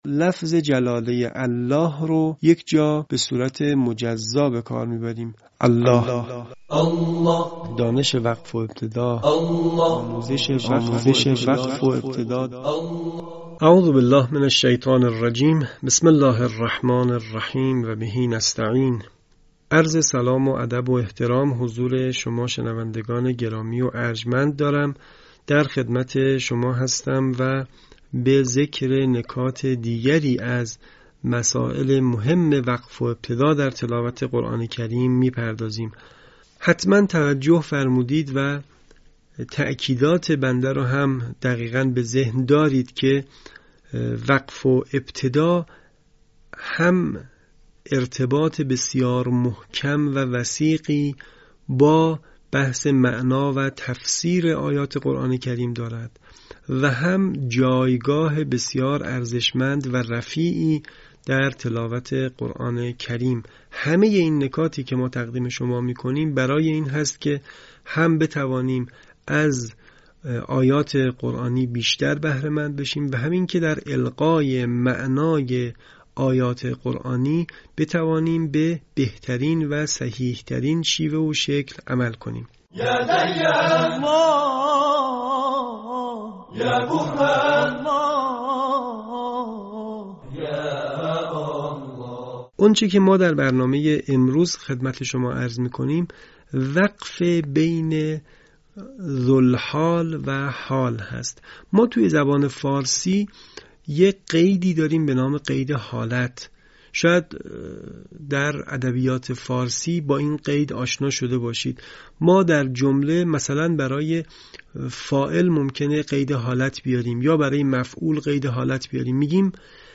آموزش وقف و ابتدا